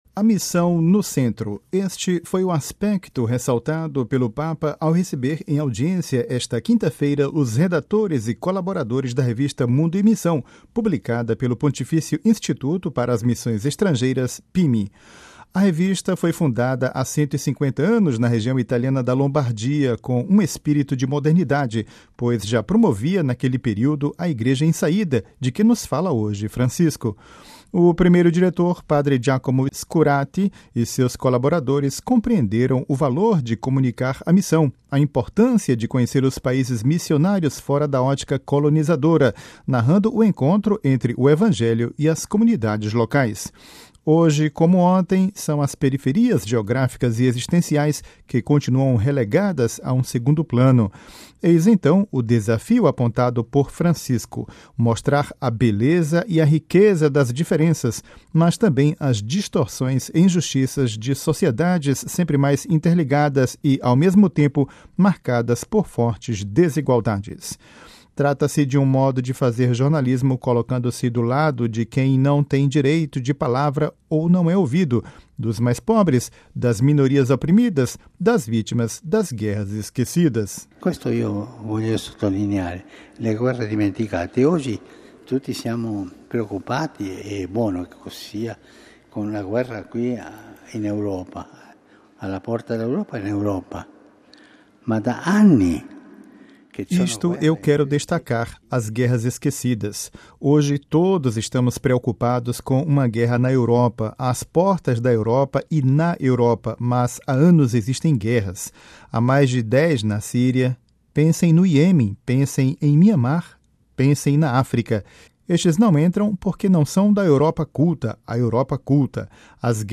Ouça a reportagem com a voz do Papa Francisco